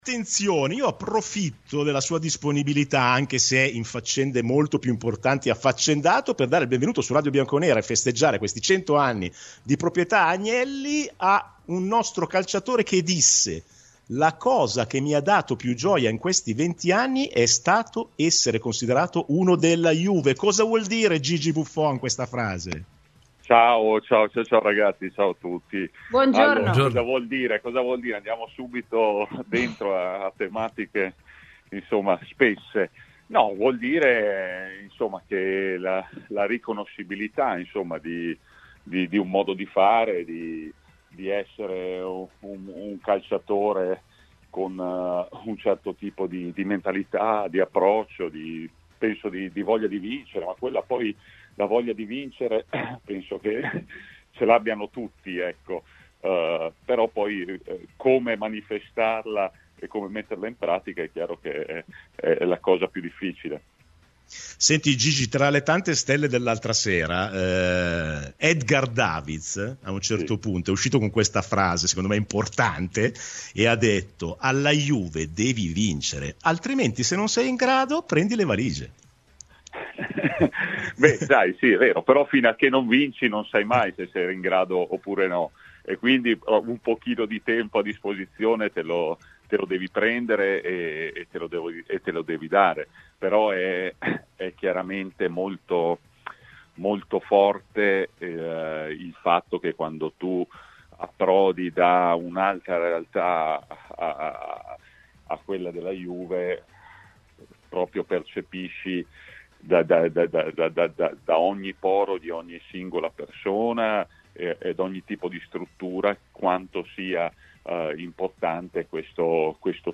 Gigi Buffon ospite a Radio Bianconera nel corso di RBN Cafe.